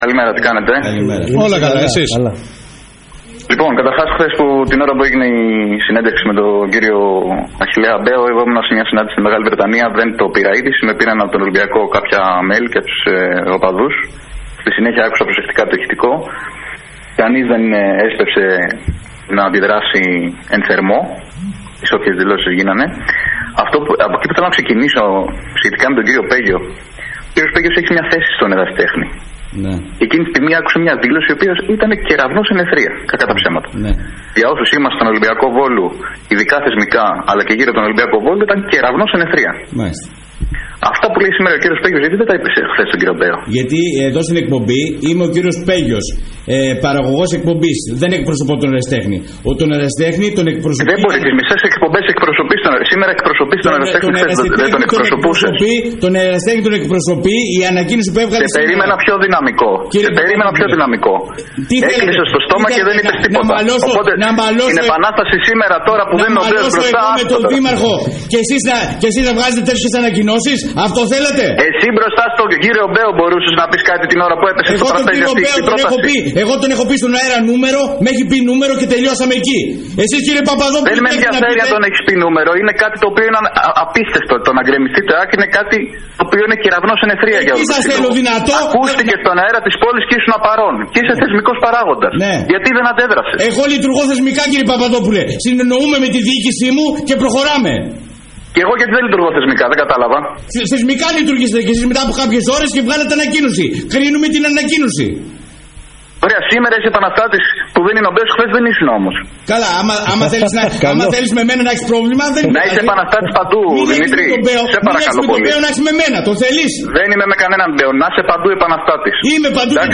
Η συνέντευξη